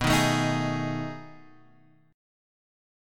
B13 chord